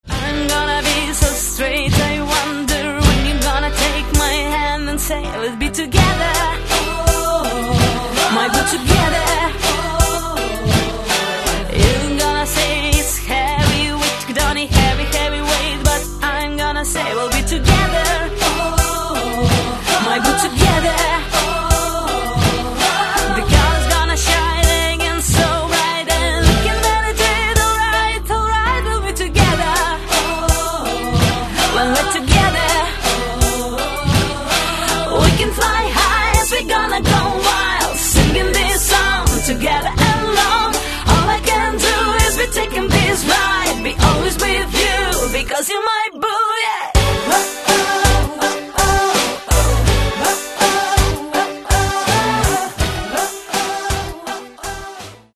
Каталог -> Поп (Легкая) -> Подвижная